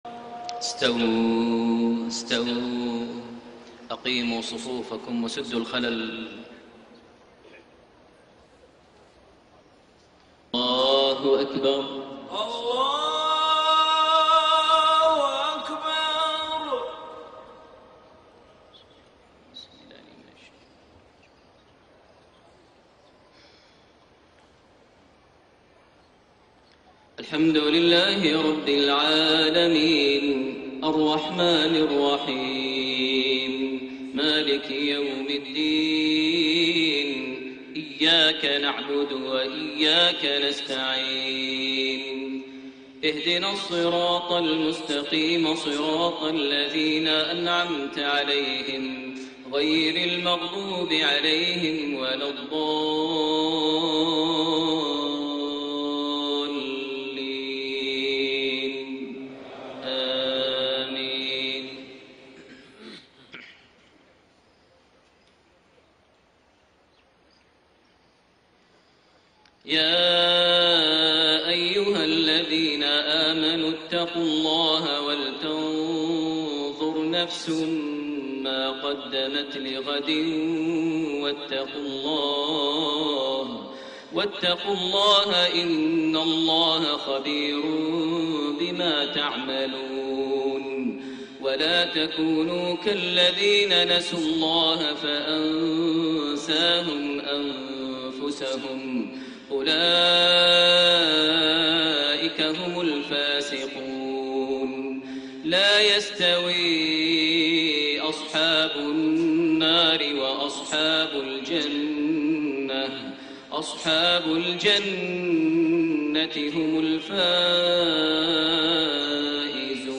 Maghrib prayer from Surah Al-Hashr > 1433 H > Prayers - Maher Almuaiqly Recitations